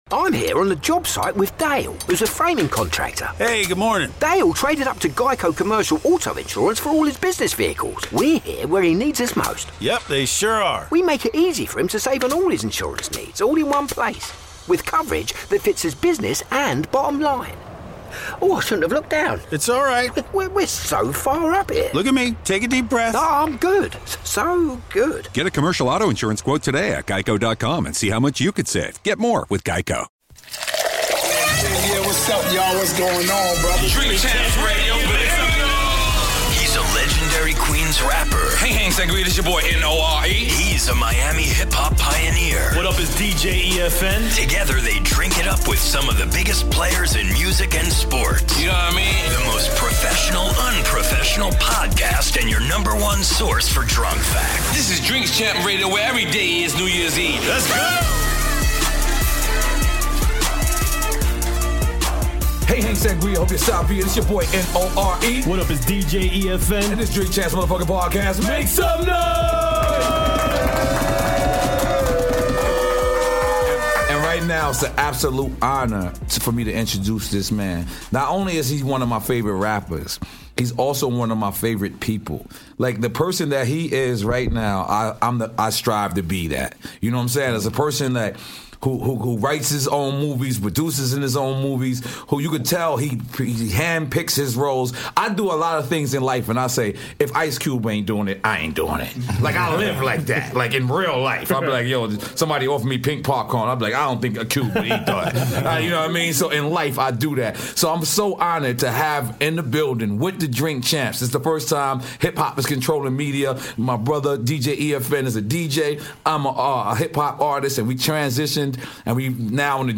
N.O.R.E. & DJ EFN are the Drink Champs. In this teaser of their sit down with Ice Cube the guys talk about Ice Cube's new film "Fist Fight" which hits theaters this weekend.